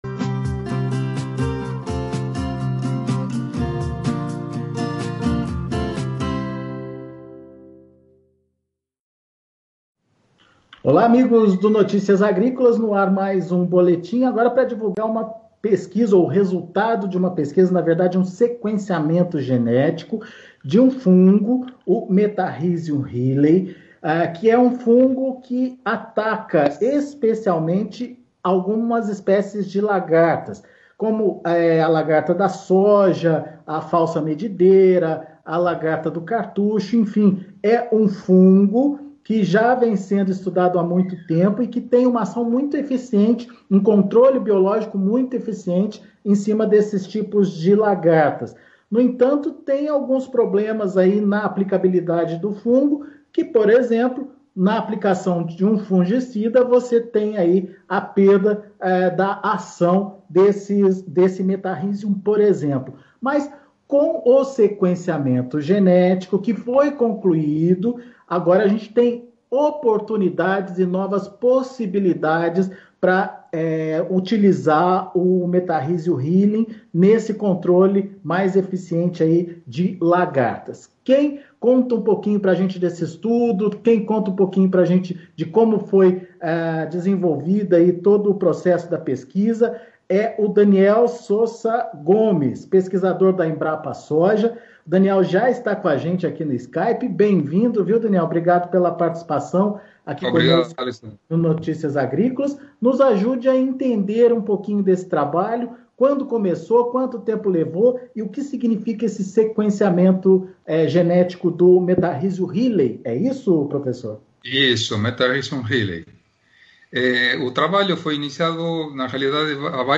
Sequenciado Genoma de Fungo na lagarta da Soja - Entrevista